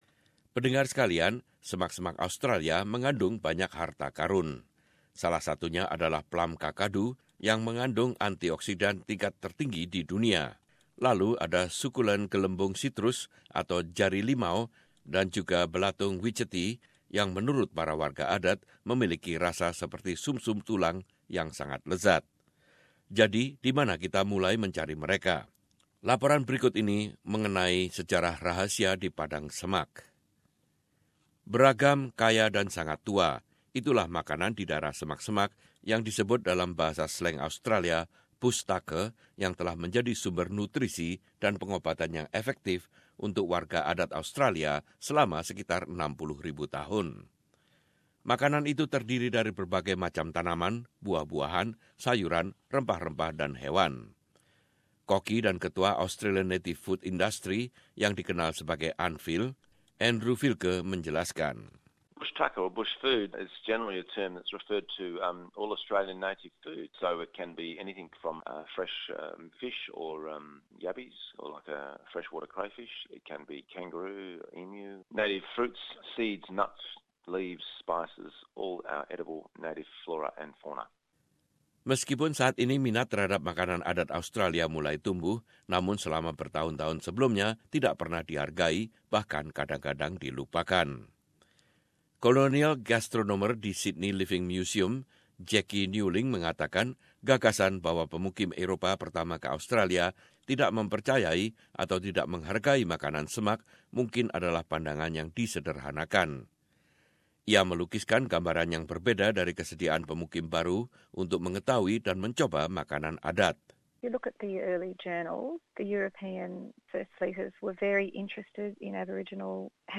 Laporan ini adalah tentang sejarah rahasia di padang semak-semak.